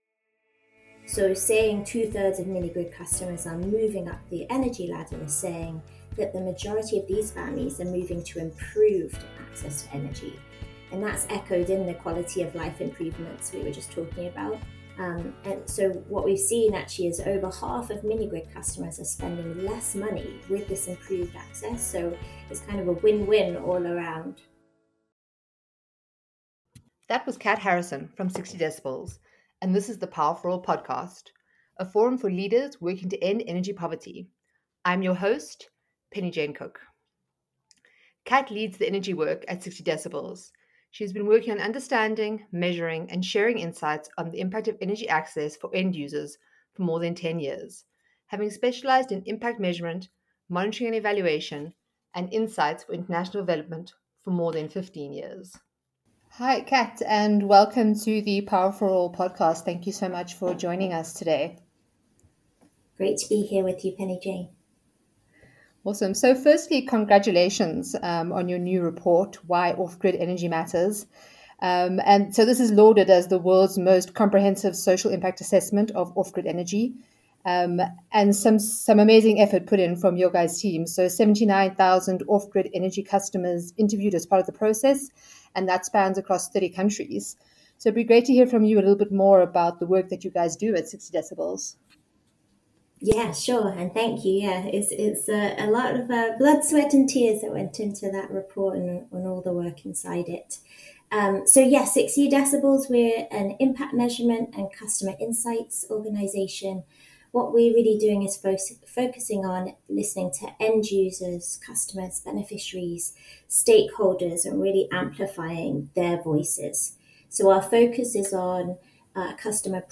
Podcasts, Interviews